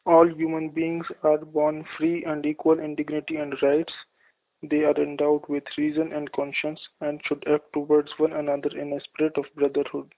Hear recordings of this text in different accents of English.
Native speakers of English
Indian English